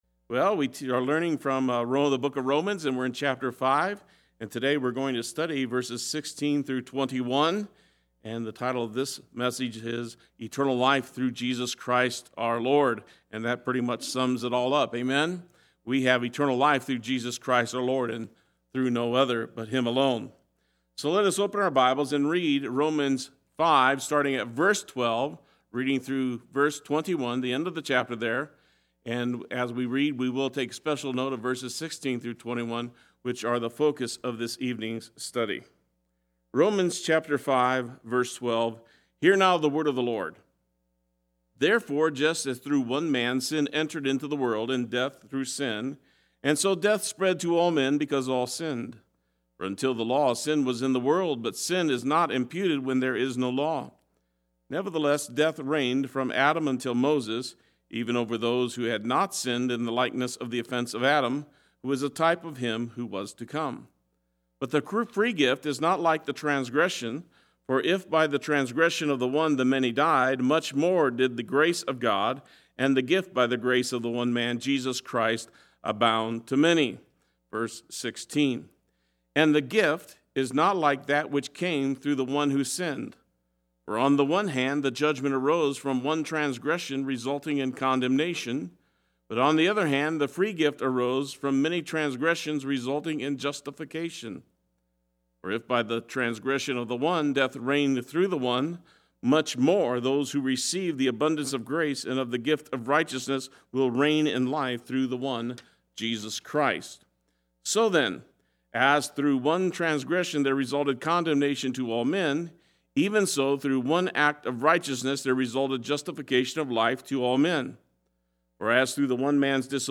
Play Sermon Get HCF Teaching Automatically.
Our Lord Wednesday Worship